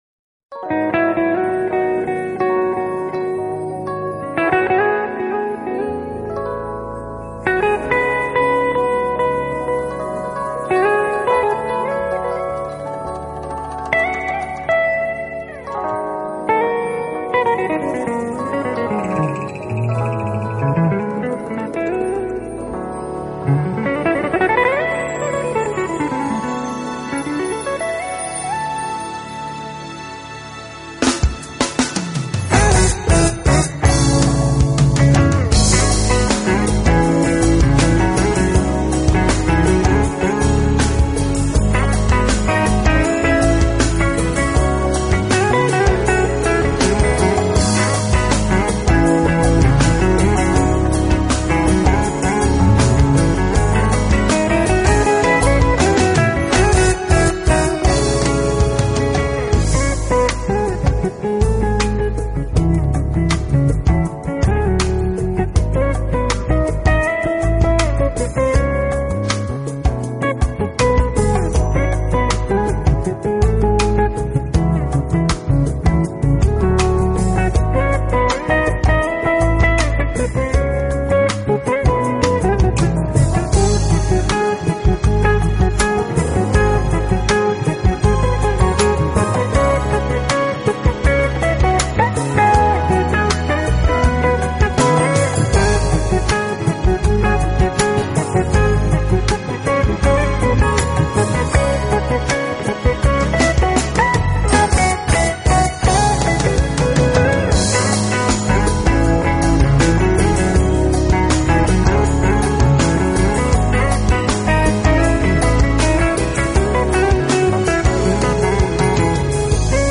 音乐类型：Jazz